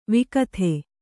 ♪ vikatha